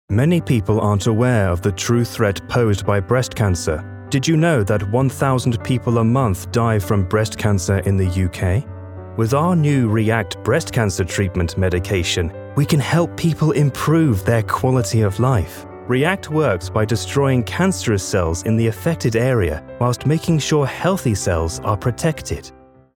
Inglés (Británico)
Profundo, Natural, Versátil, Seguro, Amable
Corporativo
Los clientes han descrito su voz como rica, resonante, profunda, templada con gravitas, muy adaptable a diferentes géneros y (casi) inconfundiblemente británica.